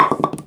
bowlingPinFall_r_3.wav